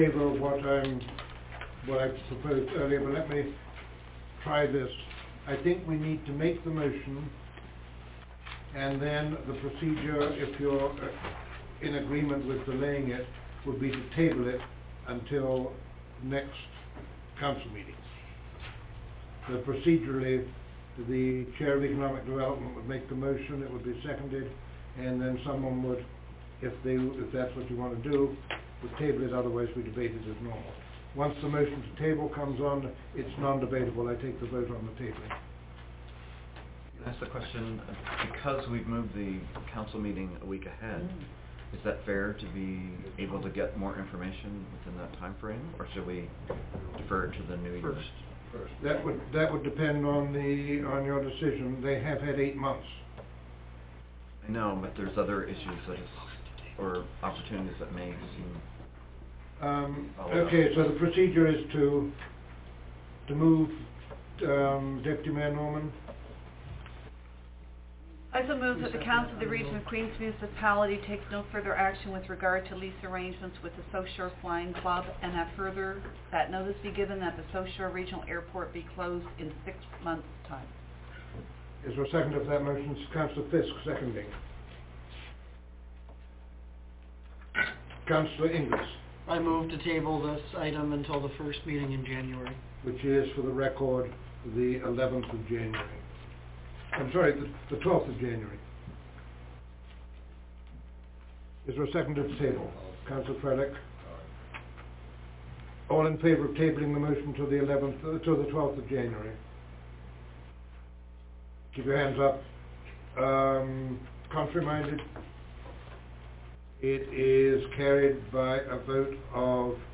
audio 2015-12-15 – Regular Council Meeting Items 8.2 – 16 Audio ( mp3, 11.72 MB )